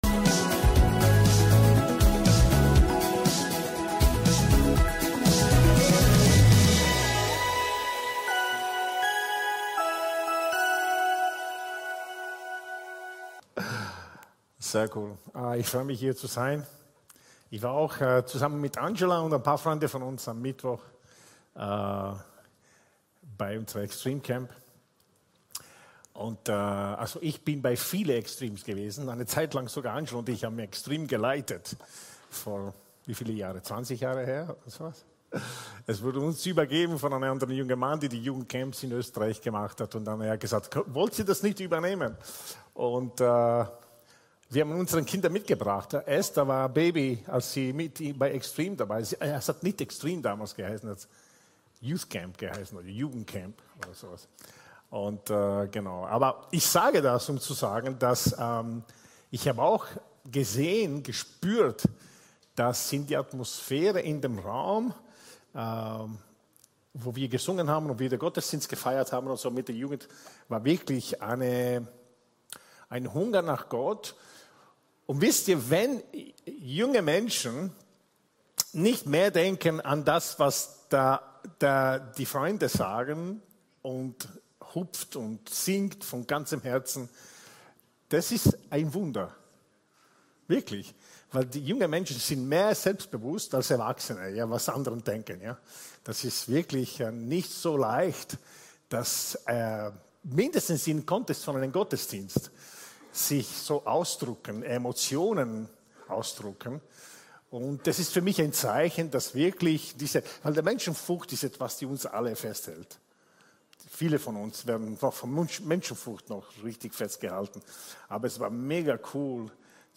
Gottesdienst aus der LIFE Church Wien.